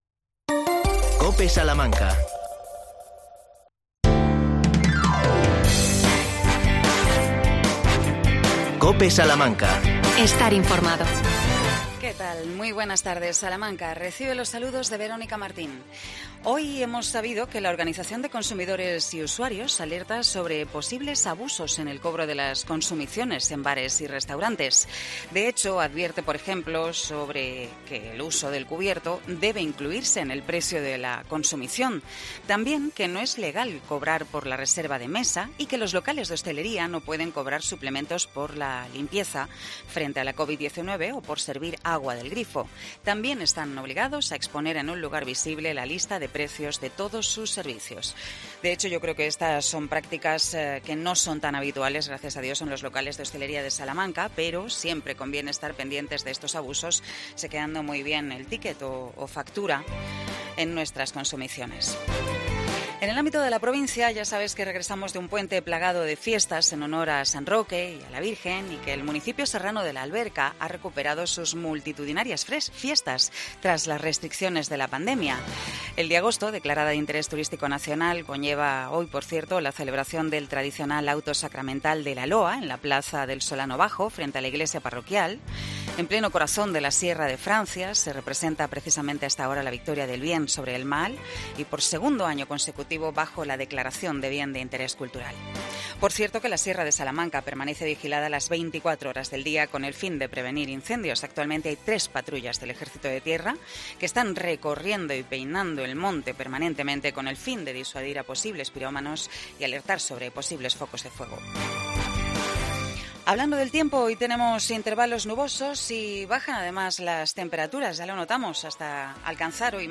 AUDIO: Fernando Castaño, concejal de turismo del Ayuntamiento de Salamanca, propone actividades para lo que queda de verano.